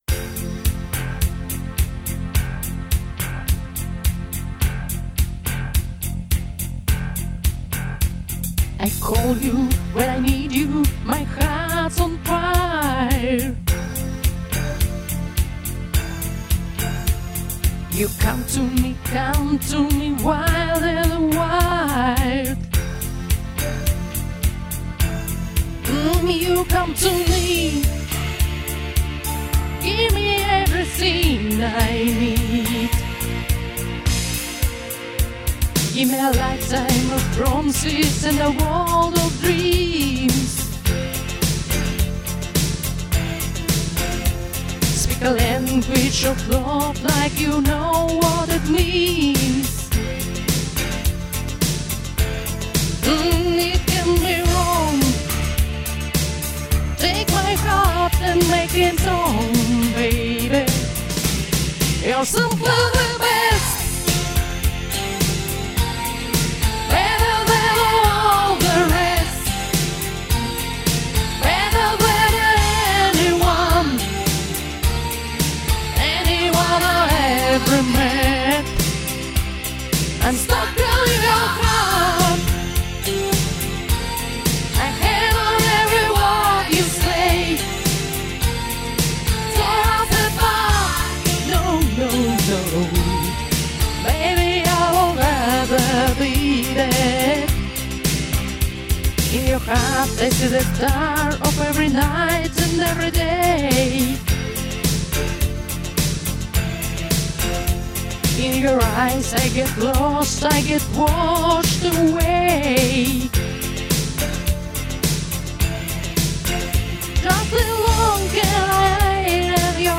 Шикарно спели!!! Столько энергии, страсти - молодцы!